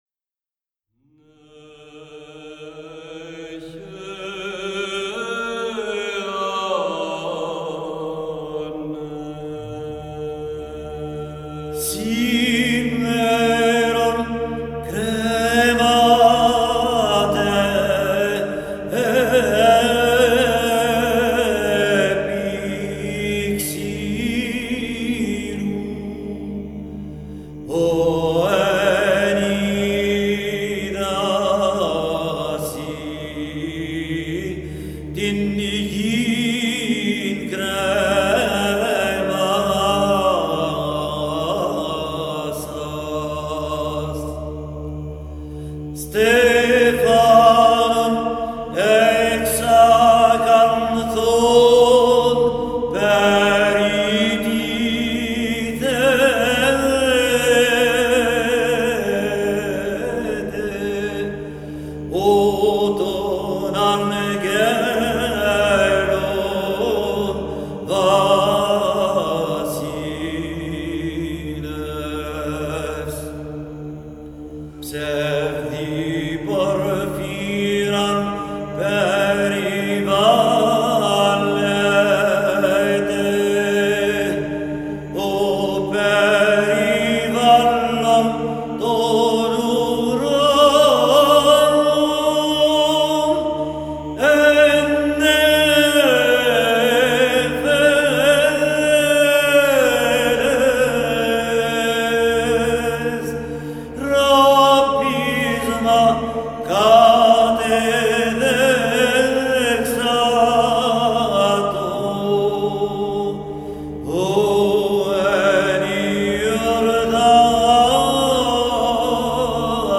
(liturgie byzantine, antienne de l'office de la Passion)